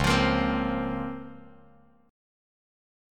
C#M13 chord